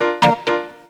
PIANO+GTR1-R.wav